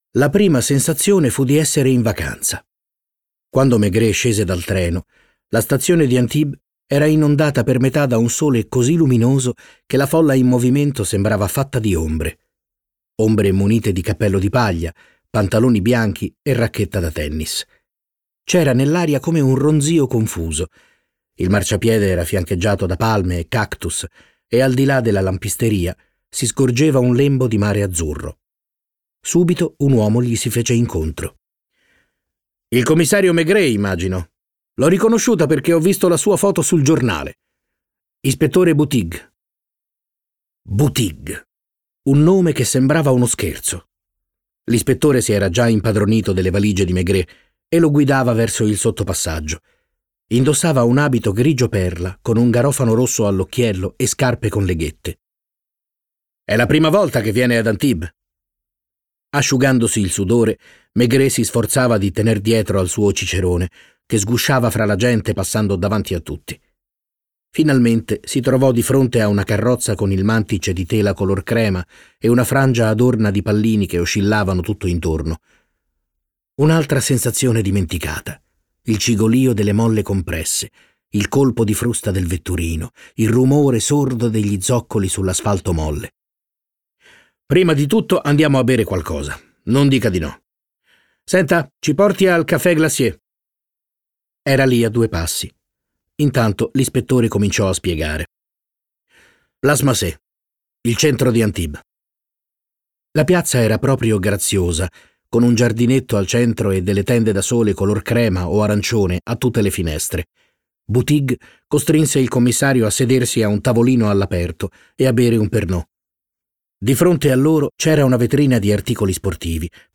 letto da Stefano Fresi
Versione audiolibro integrale